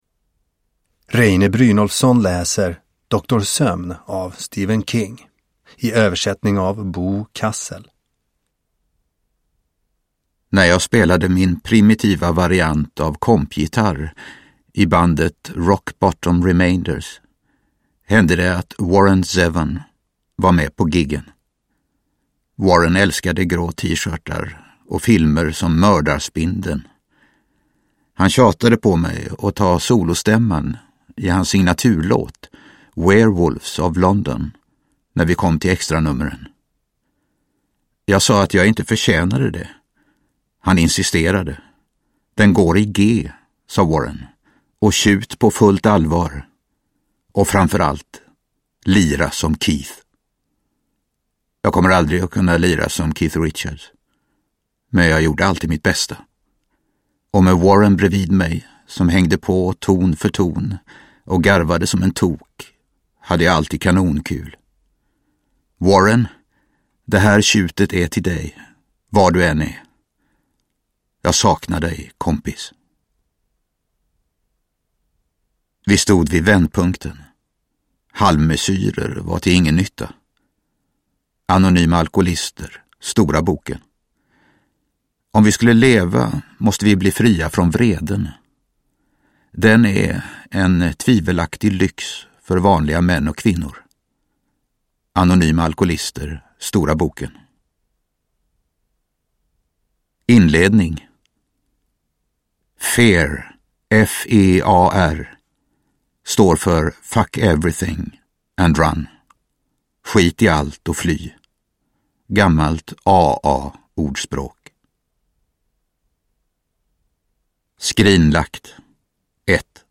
Doktor Sömn – Ljudbok – Laddas ner
Uppläsare: Reine Brynolfsson